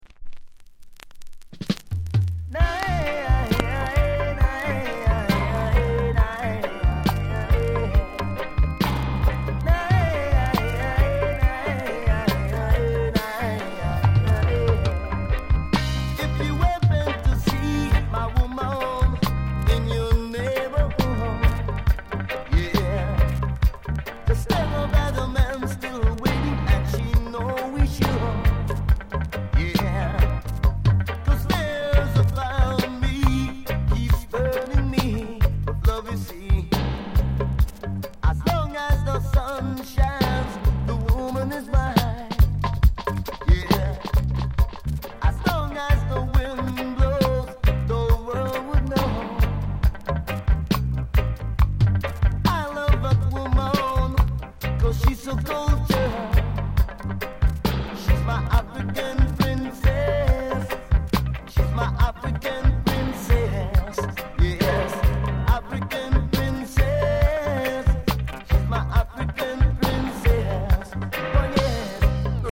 高品質 ONE DROP～ROOTS
概ね美盤ですが、両面 あたまにパチつく箇所あり。